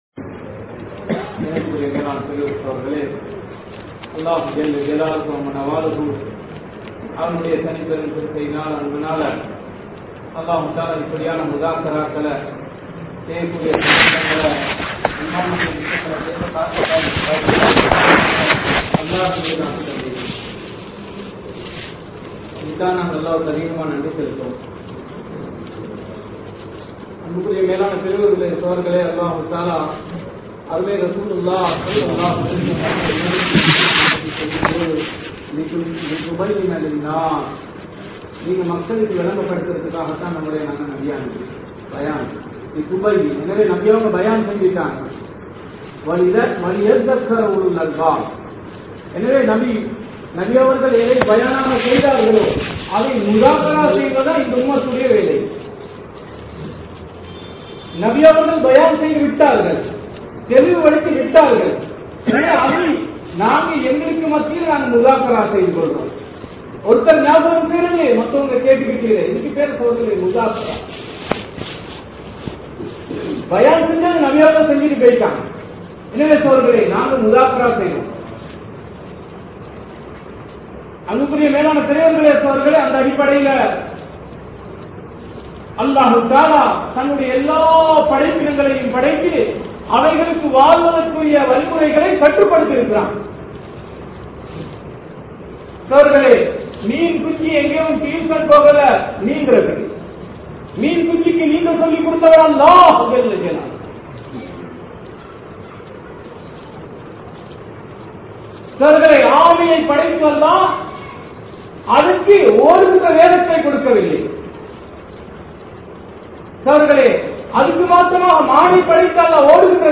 Nimmathi Veanduma? (நிம்மதி வேண்டுமா?) | Audio Bayans | All Ceylon Muslim Youth Community | Addalaichenai
Sungavila Mansoor Colany Jumua Masjidh